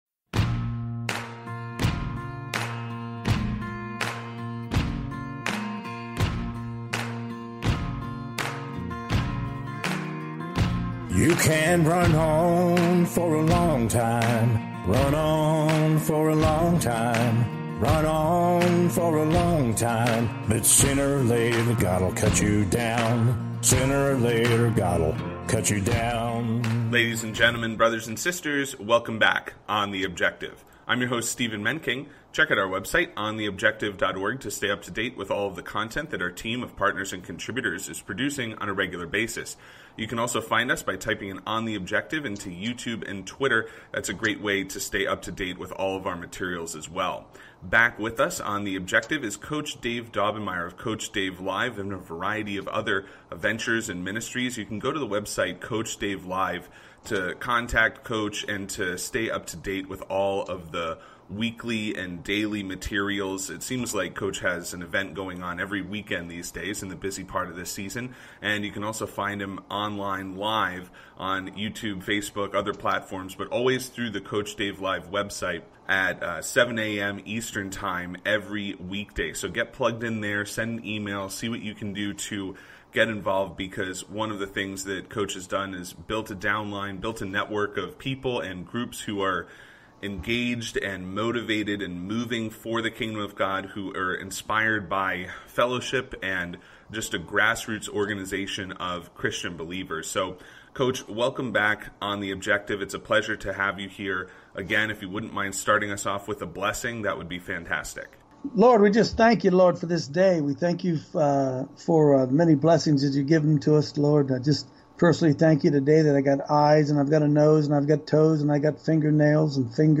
On the Objective Interview – Kanye West, Christianity, and Collaboration